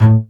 STR BASS M1X.wav